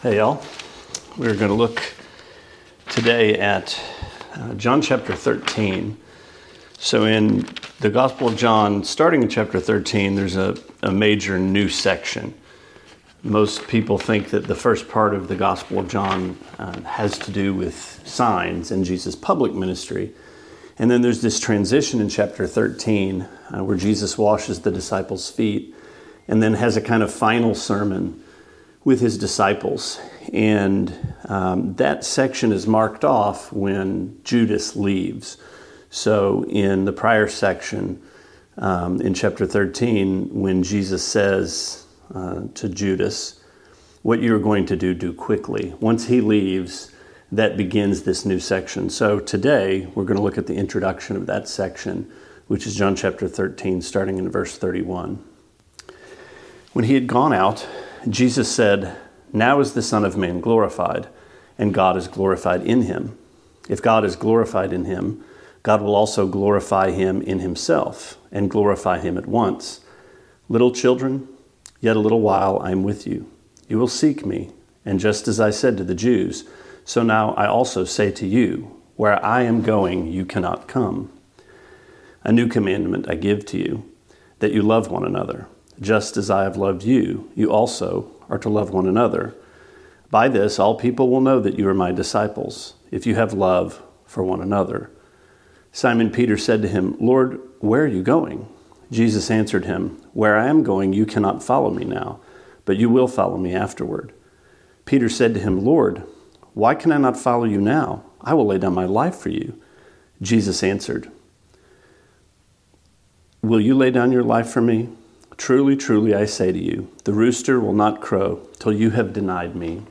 Sermonette 5/19: John 13:31-38: A New Commandment